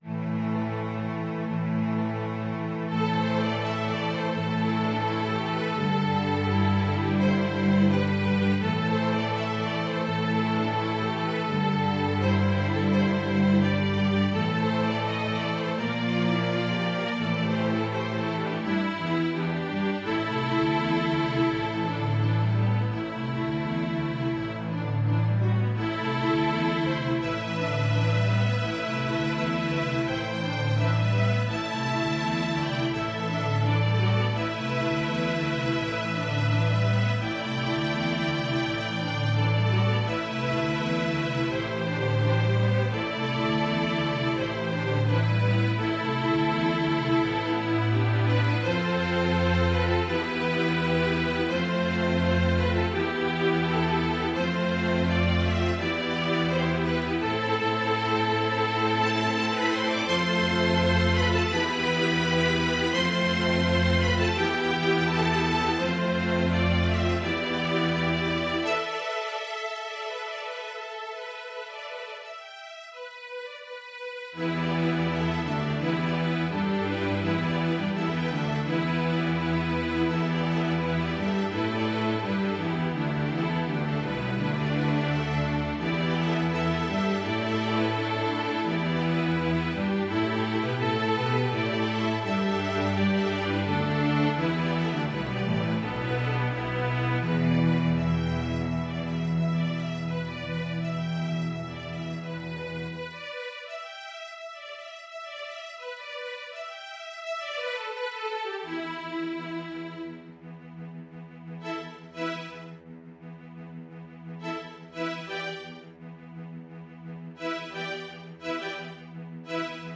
String Orchestra